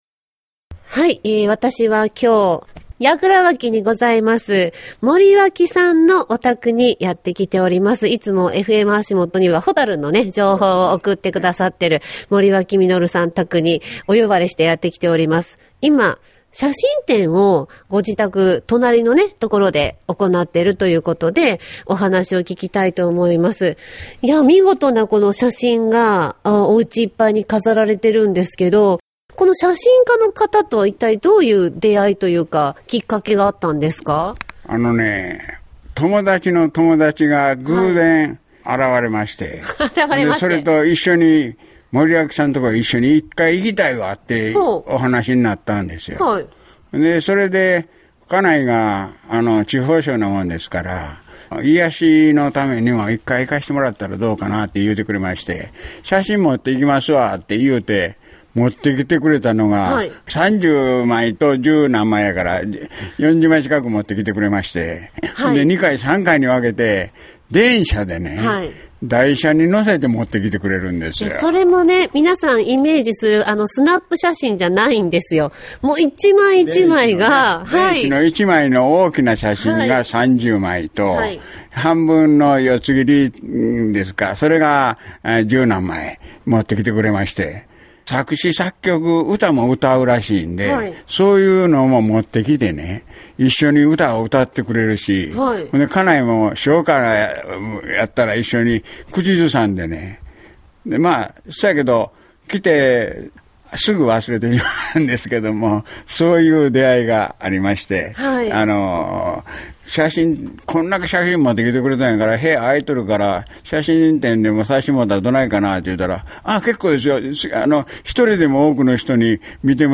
【ラジオのインタビュー番組で紹介】「FMはしもと」で放送された音源です。
和歌山での写真展をラジオ番組が取材して放送して下さったものです。